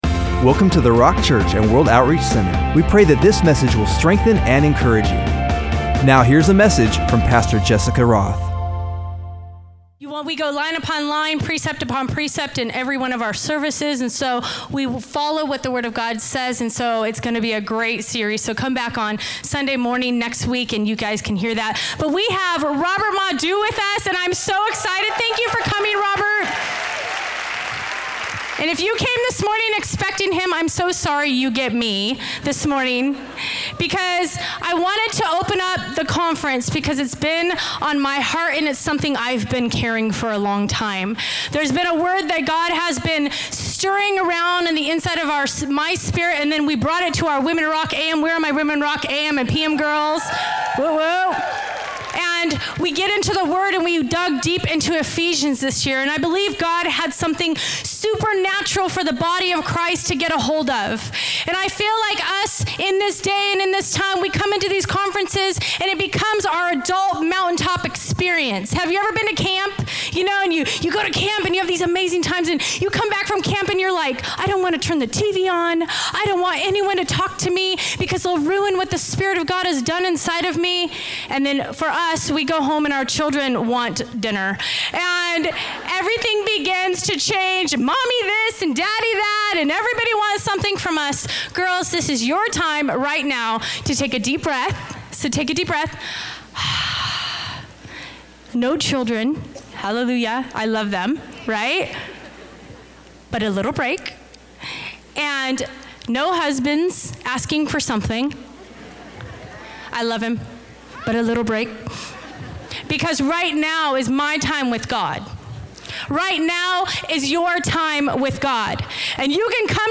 Wonder Conference